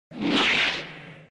Download Dragon Ball Fly Up sound effect for free.